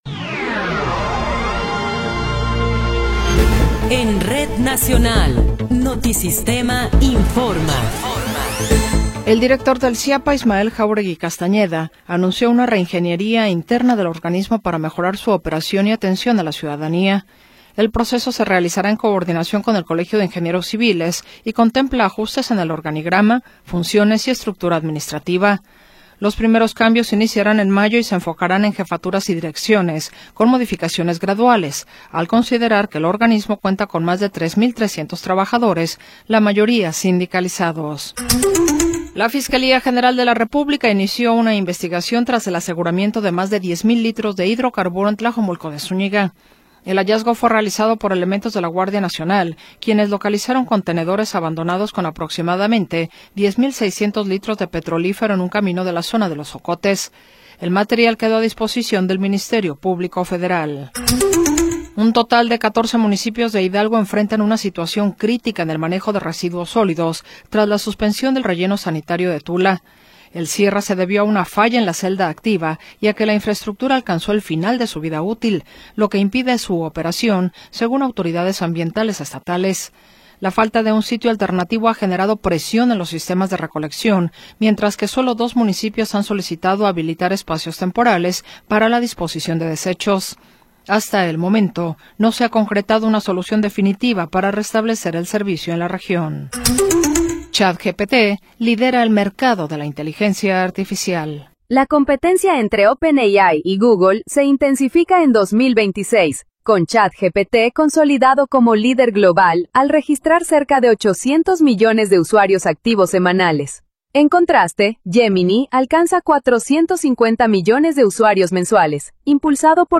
Noticiero 16 hrs. – 18 de Abril de 2026
Resumen informativo Notisistema, la mejor y más completa información cada hora en la hora.